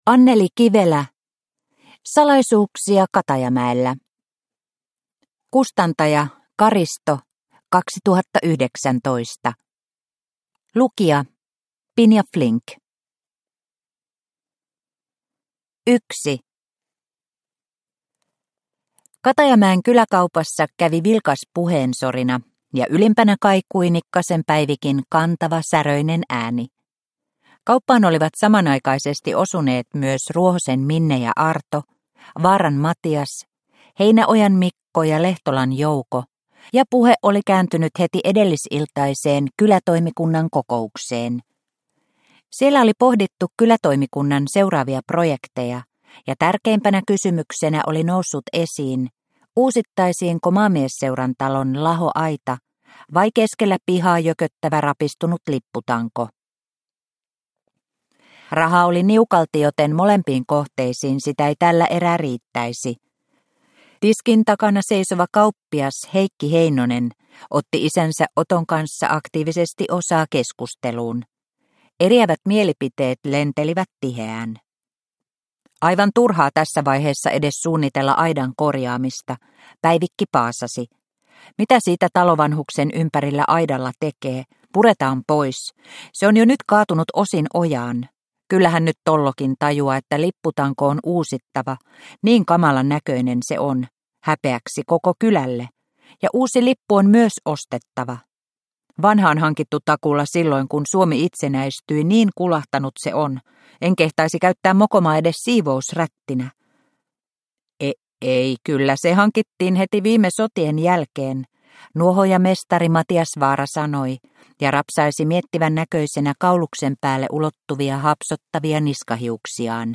Salaisuuksia Katajamäellä – Ljudbok – Laddas ner